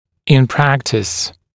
[ɪn ‘præktɪs][ин ‘прэктис]на практике